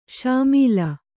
♪ śamīla